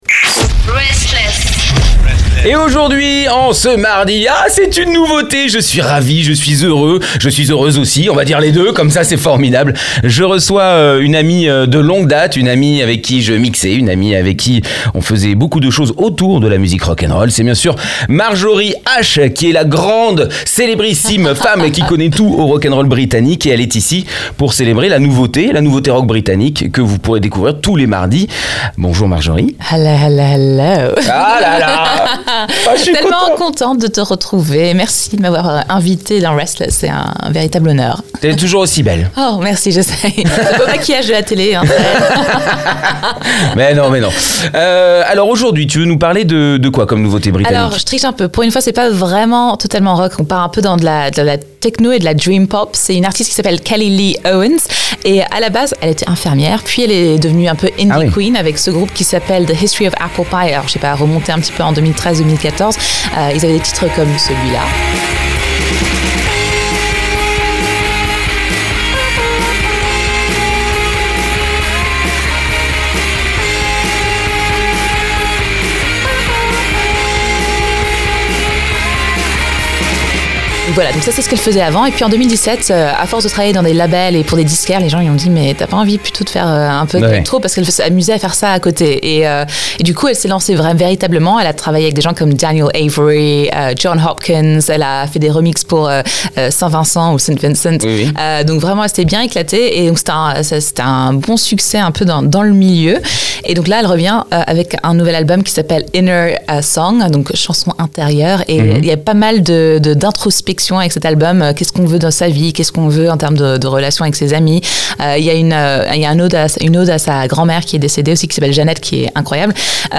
Elle est de retour avec un deuxième album solo alliant techno minimaliste et dream-pop.